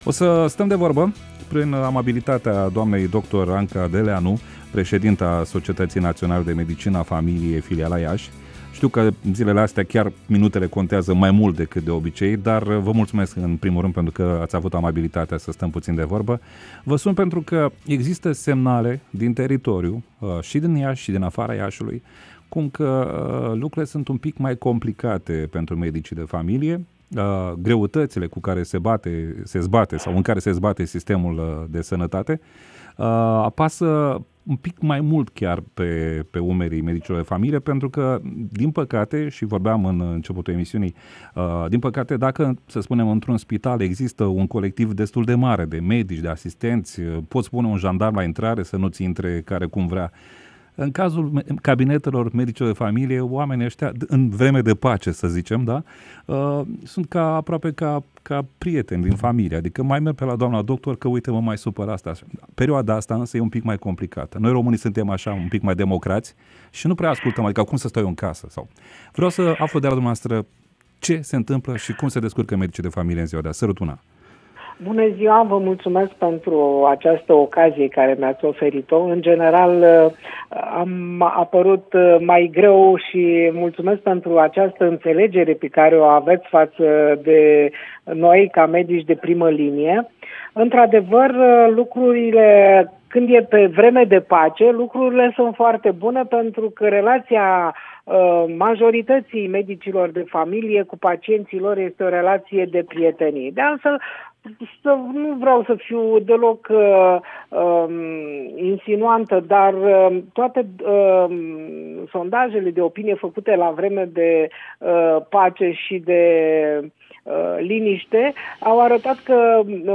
Despre medicii de familie, medici aflați în prima linie - Interviu